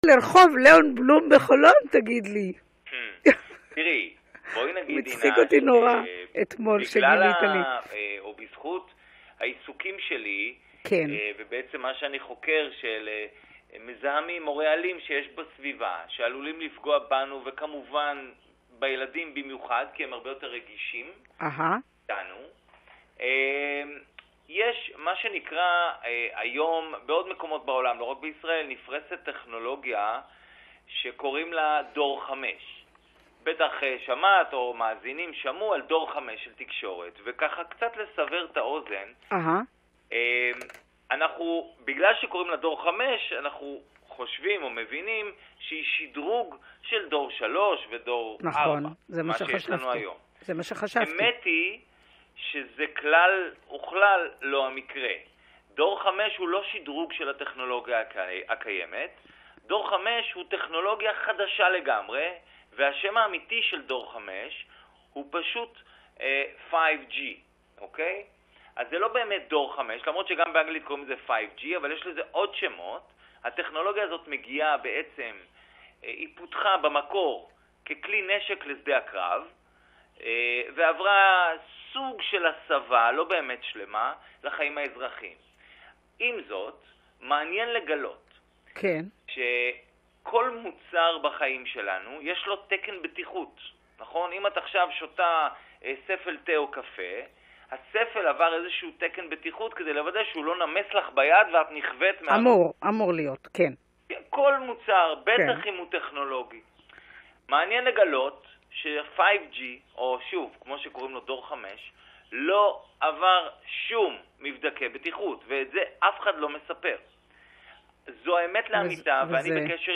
ראיון ברדיו קסם על רחוב חכם לאון בלום בחולון